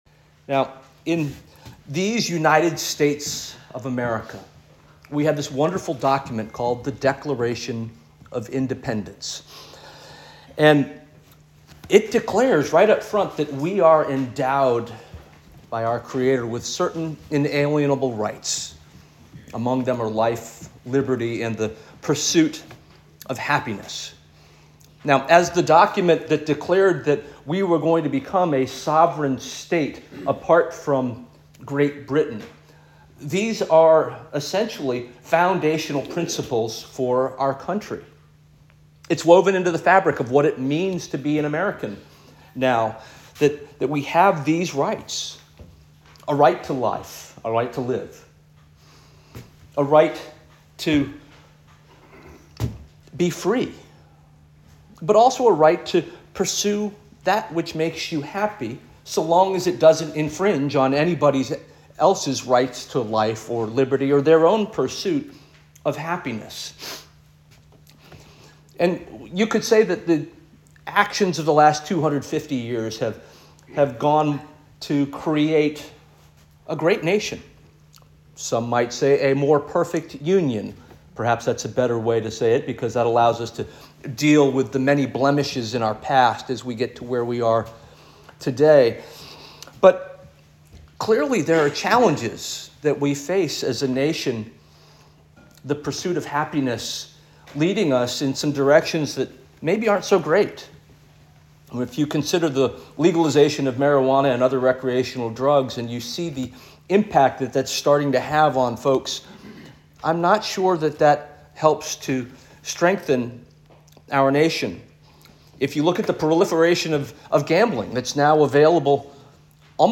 December 15 2024 Sermon - First Union African Baptist Church